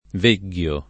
vegliare
vegliare v.; veglio [ v % l’l’o ], vegli